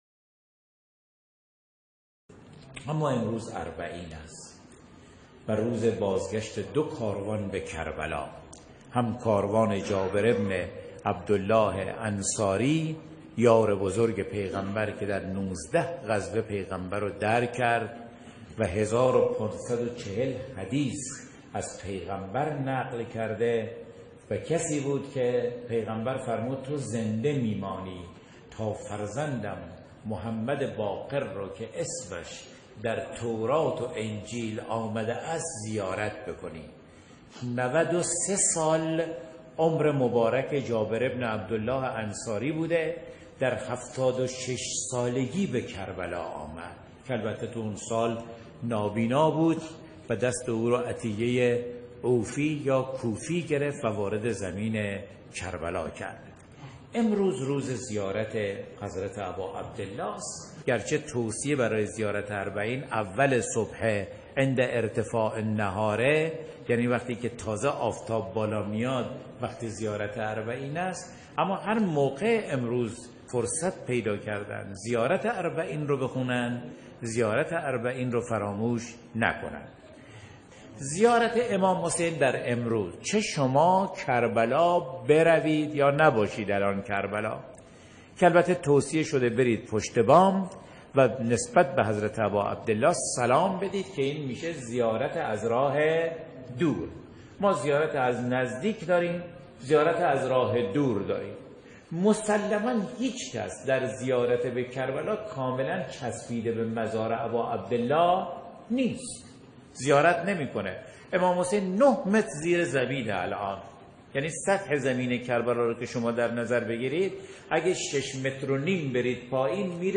بخشی از سخنرانی